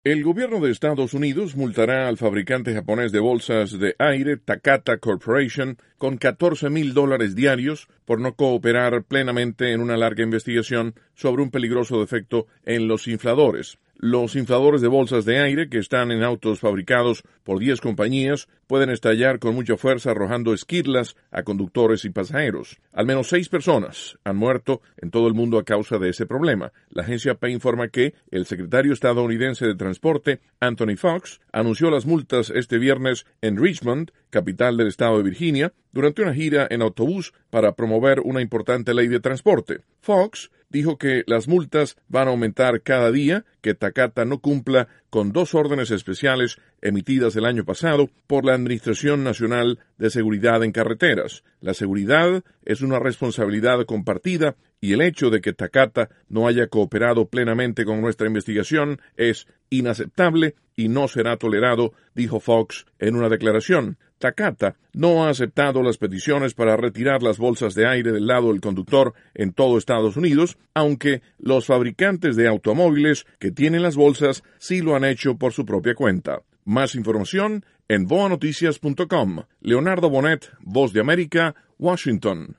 informa desde Washington